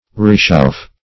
R'echauff'e \R['e]`chauf`f['e]"\, n. [F., orig. p.p. of